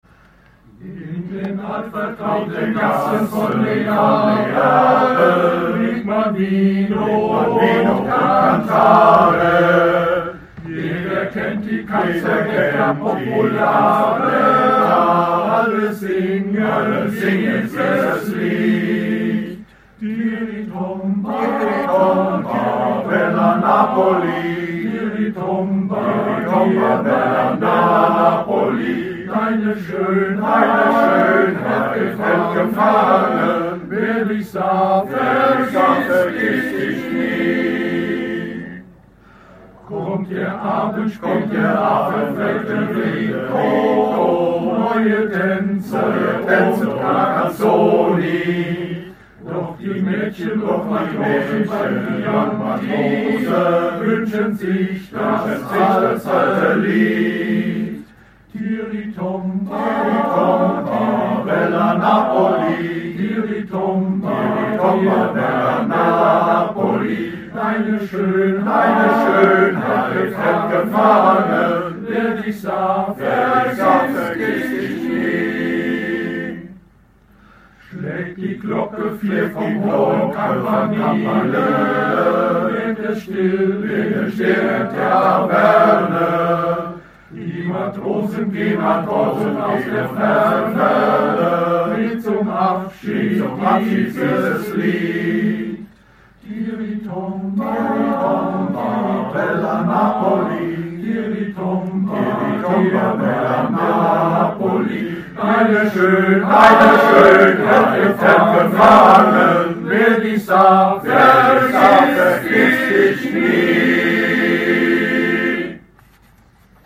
Wallufer Männerchöre